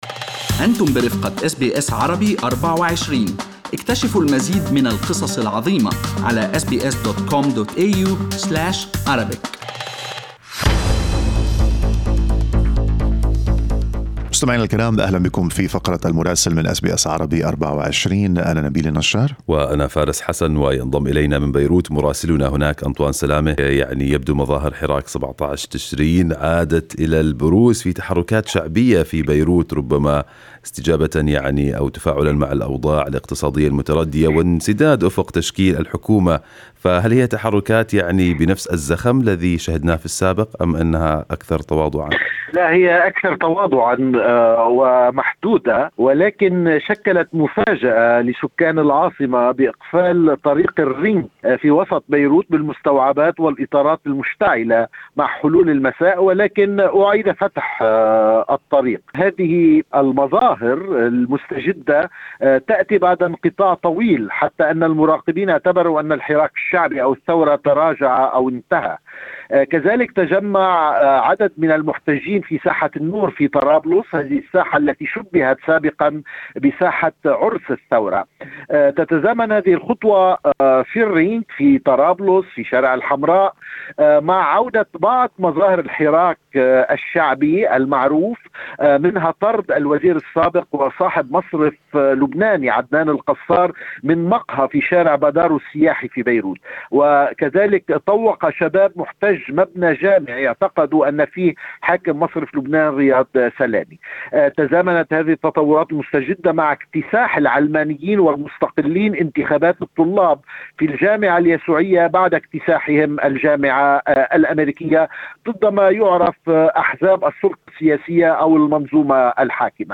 يمكنكم الاستماع إلى تقرير مراسلنا في بيروت بالضغط على التسجيل الصوتي أعلاه.